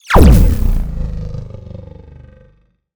sci-fi_shield_power_deflect_boom_03.wav